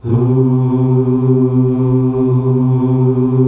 HUUUH   C.wav